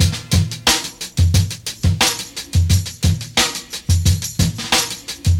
118 Bpm Old School Breakbeat F# Key.wav
Free breakbeat - kick tuned to the F# note. Loudest frequency: 2424Hz
.WAV .MP3 .OGG 0:00 / 0:05 Type Wav Duration 0:05 Size 931,83 KB Samplerate 44100 Hz Bitdepth 16 Channels Stereo Free breakbeat - kick tuned to the F# note.
118-bpm-old-school-breakbeat-f-sharp-key-kyj.ogg